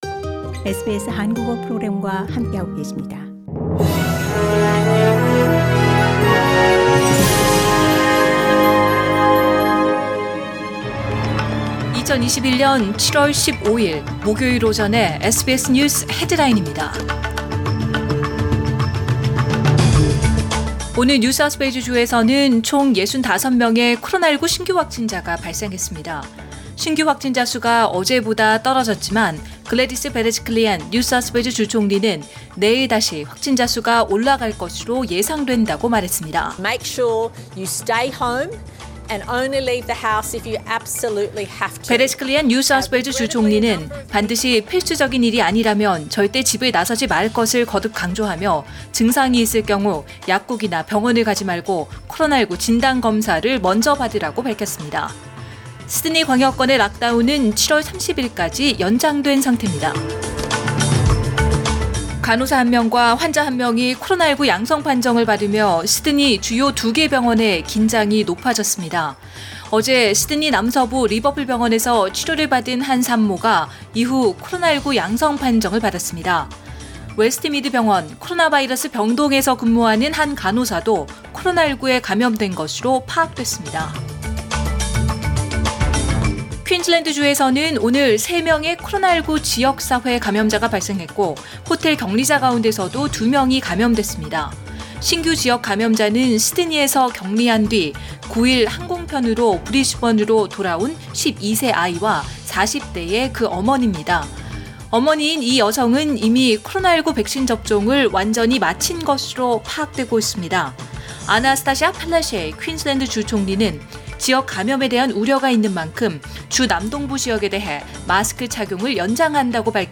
2021년 7월 15일 목요일 오전의 SBS 뉴스 헤드라인입니다.